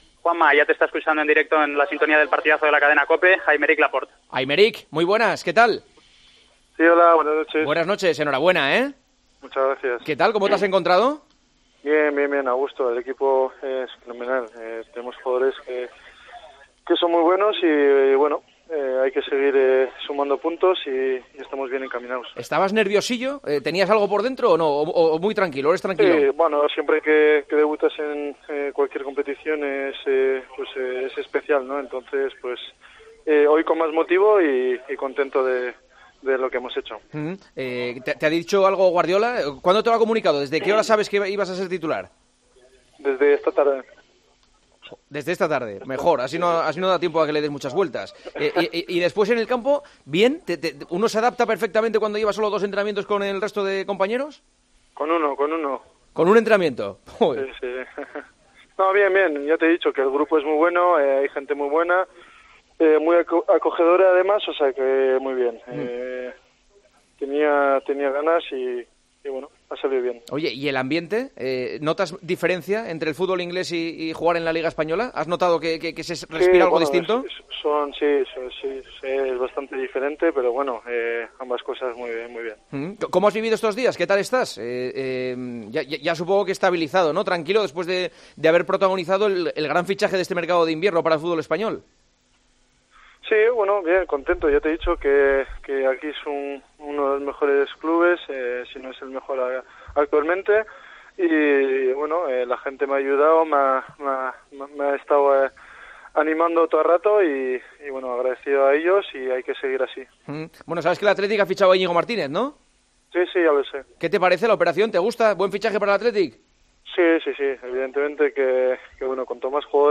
Entrevistas en El Partidazo de COPE